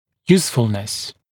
[‘juːsfəlnəs][‘йу:сфэлнэс]полезность, пригодность